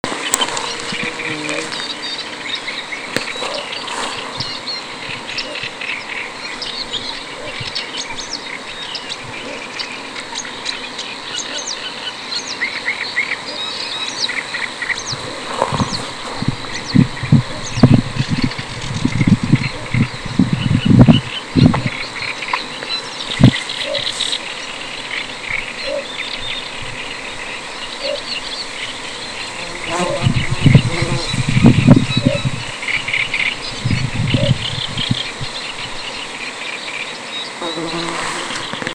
Mazais dumpis, Ixobrychus minutus
StatussDzied ligzdošanai piemērotā biotopā (D)